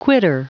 Prononciation du mot quitter en anglais (fichier audio)
Prononciation du mot : quitter